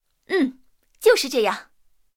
IS-7强化语音.OGG